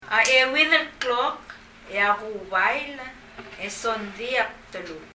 発音
bdeluk　　[(p)ðɛlək]　　　髪　　hair
子音の前の b は[p]音になります　　でもほとんど聞こえません。